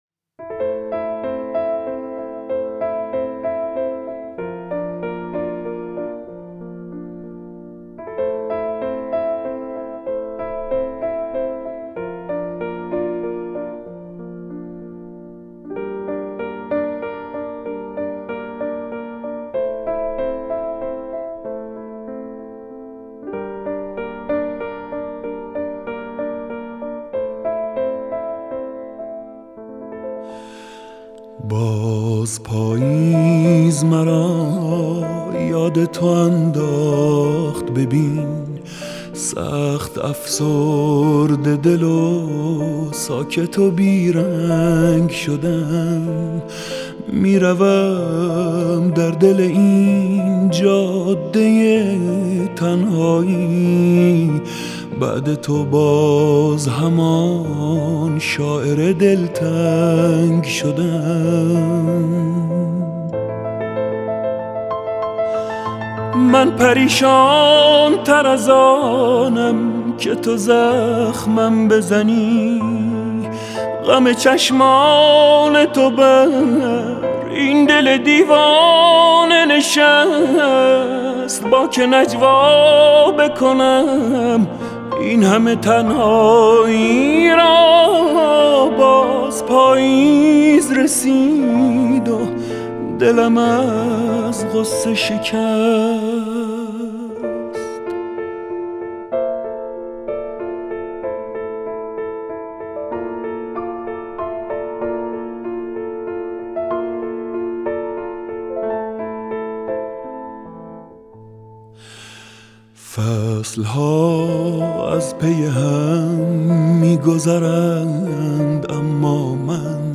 سنتی